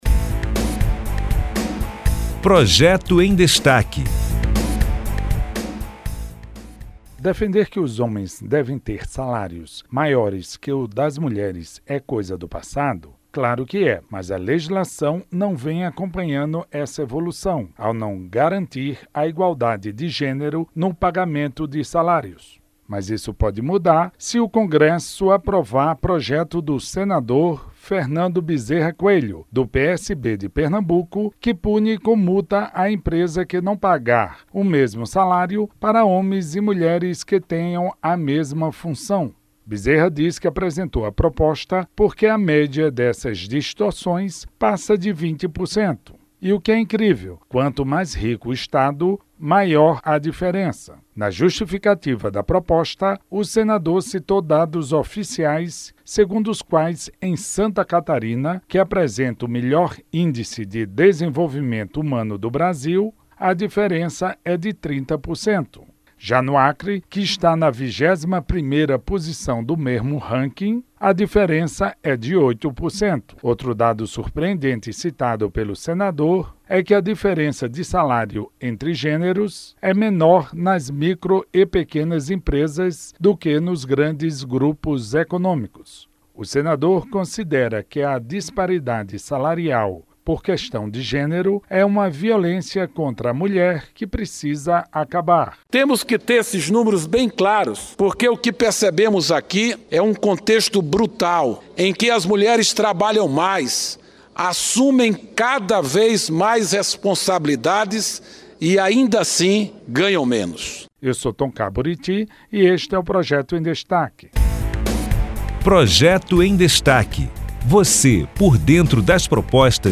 O PLS 88/2015, de autoria do senador Fernando Bezerra Coelho (PSB-PE),  pune  com multa empresas que não pagarem salários iguais para mulheres e homens que desempenharem a mesma função. Saiba mais detalhes da proposta com o jornalista